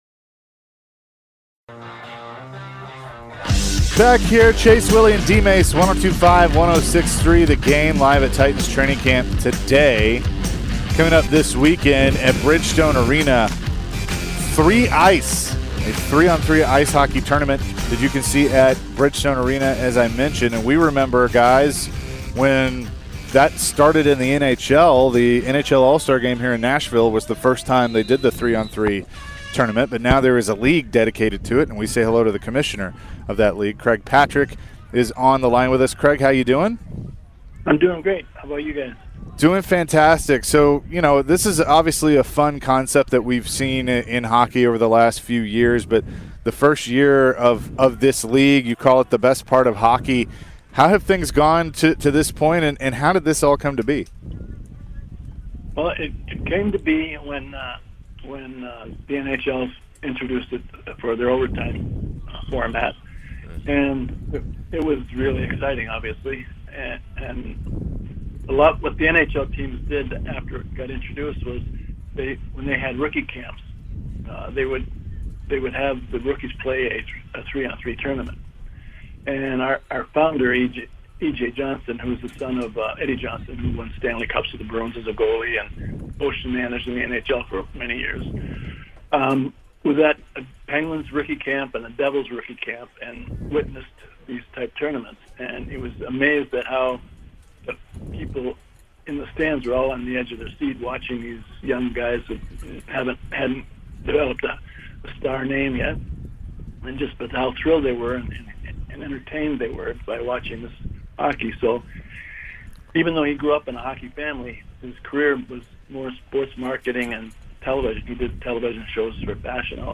Craig Patrick Full Interview (08-02-22)